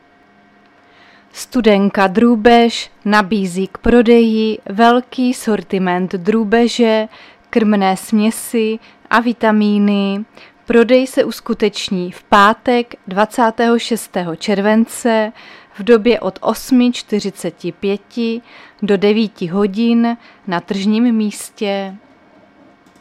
Záznam hlášení místního rozhlasu 23.7.2024